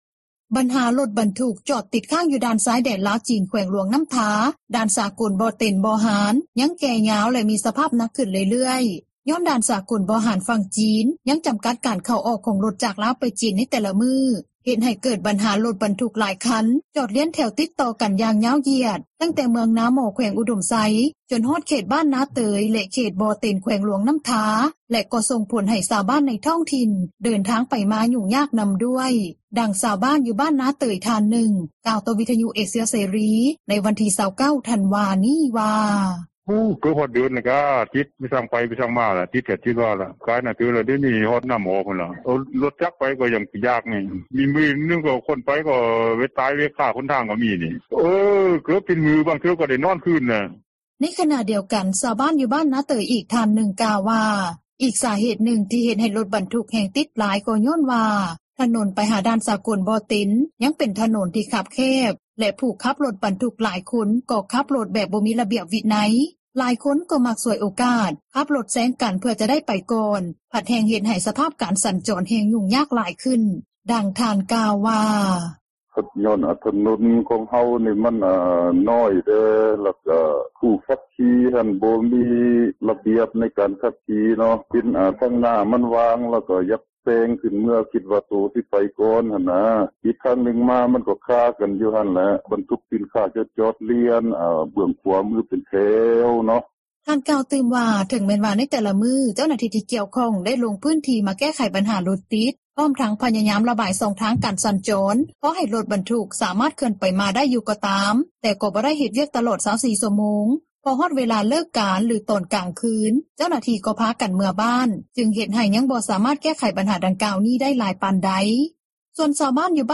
ສ່ວນຊາວບ້ານ ຢູ່ບ້ານບໍ່ເຕັນ ກໍປະສົບບັນຫາຫຍຸ້ງຍາກໃນການເດີນທາງ ທັງອອກໄປຊື້ເຄື່ອງຢູ່ຕລາດ ຫຼືໄປໂຮງໝໍ ຊຶ່ງສະພາບຣົຖຕິດດັ່ງກ່າວນີ້ ກໍໄດ້ກິນເວລາມາດົນເຕີບແລ້ວ ແລະກໍບໍ່ຮູ້ວ່າຈະແກ່ຍາວໄປຮອດມື້ໃດ ຈຶ່ງຈະສາມາດແກ້ໄຂໄດ້, ດັ່ງຊາວບ້ານ ບ້ານບໍ່ເຕັນ ນາງນຶ່ງ ກ່າວວ່າ:
ສ່ວນຜູ້ປະກອບການຣົຖບັນທຸກທີ່ຍັງຕິດຄາ ຢູ່ຕາມເສັ້ນທາງດັ່ງກ່າວ ຫຼາຍຄົນກໍໄດ້ຮັບຜົລກະທົບຫຼາຍຂຶ້ນ ແລະກໍເຊື່ອວ່າບັນຫານີ້ຈະໝົດໄປກໍຕໍ່ເມື່ອດ່ານສາກົລຝັ່ງຈີນ ປ່ອຍໃຫ້ຣົຖບັນທຸກຈາກຝັ່ງລາວ ເຂົ້າໄປຈີນຫຼາຍຕາມປົກກະຕິ ແຕ່ກໍຍັງບໍ່ຮູ້ວ່າຈະເກີດຂຶ້ນມື້ໃດ, ດັ່ງເຈົ້າຂອງຣົຖບັນທຸກ ທ່ານນຶ່ງ ກ່າວວ່າ: